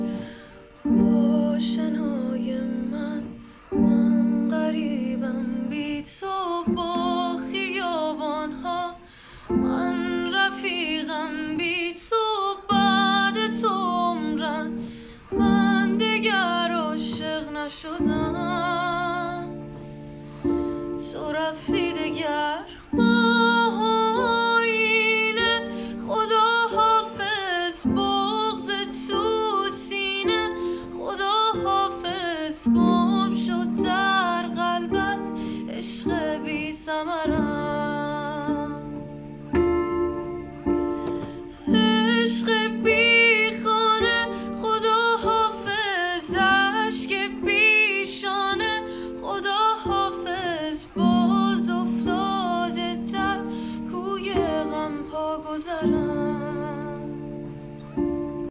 Ba Sedaye Dokhtar